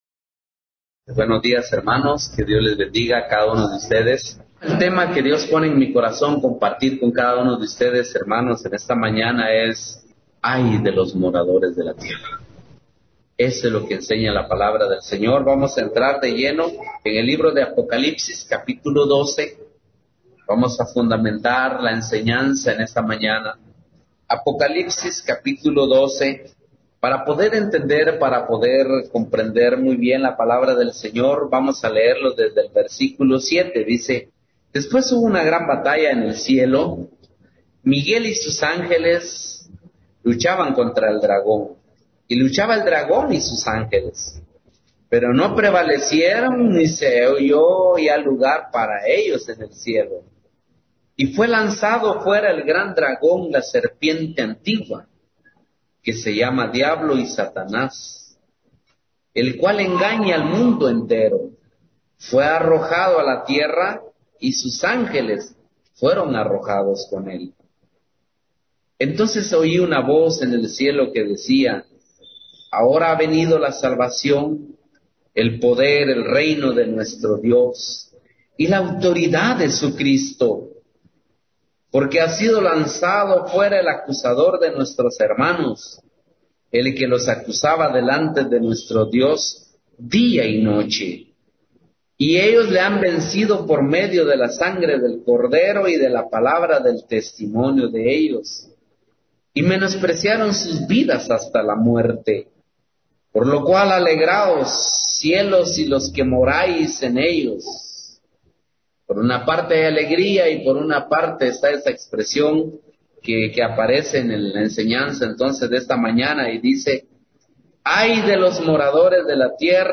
Series: Servicio General